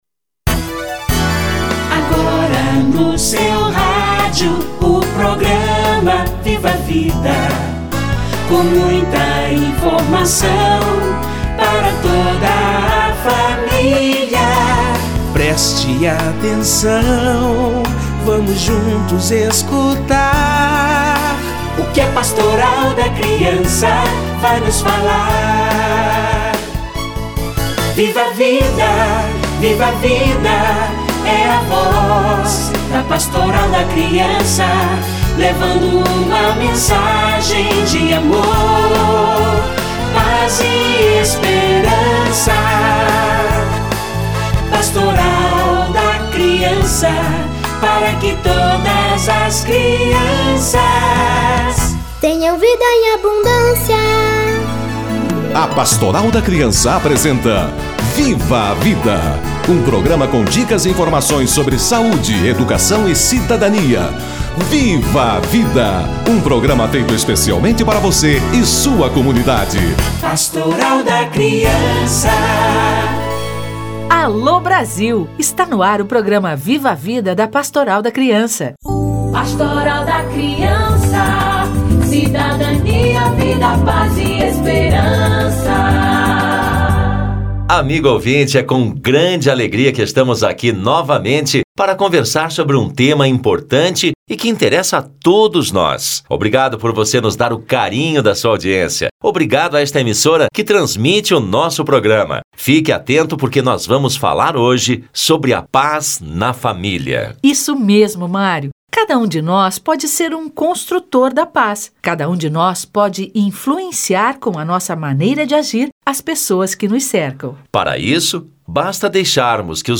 A paz começa em casa - Entrevista